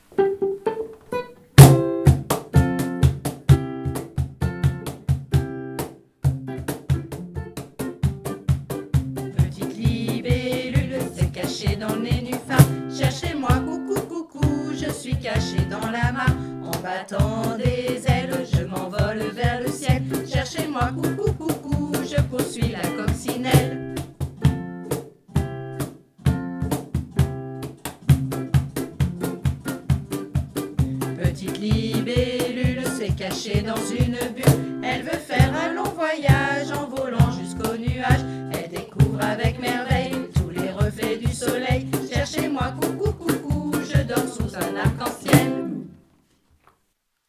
Des comptines pour les petits :
7 assistantes maternelles du RPE des Côteaux et du Plateau ont  collaboré pour mettre en musique cinq comptines qui ont été mises en musique avec des enseignants de l'école Intercommunale de musique et danse de Arche Agglo, et que vous pouvez retrouver ici.